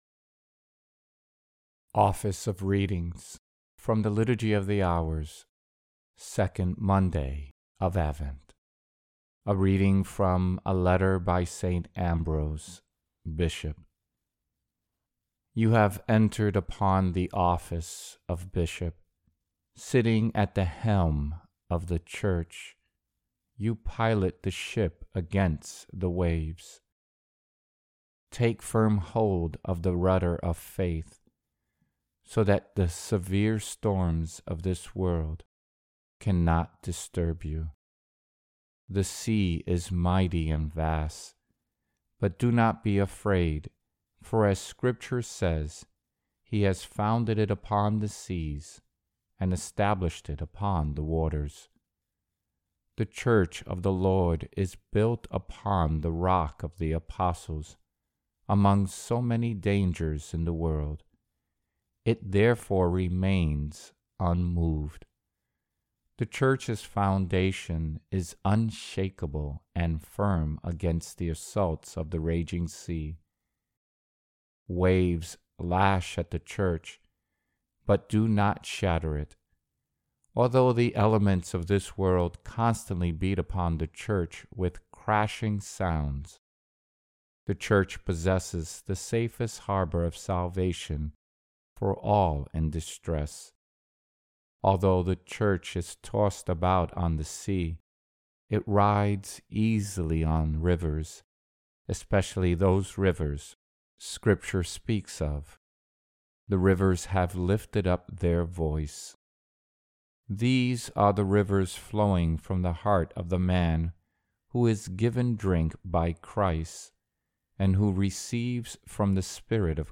Office of Readings – 2nd Monday of Advent